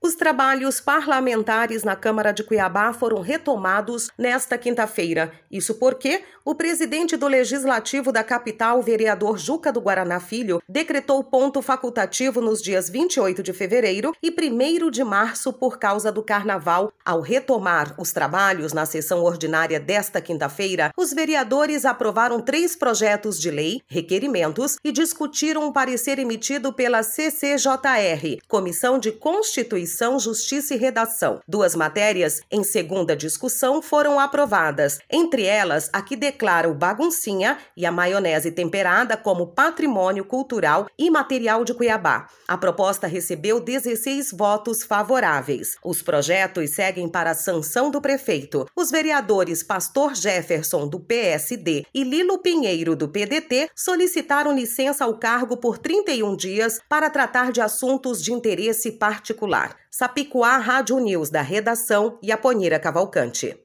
Voz: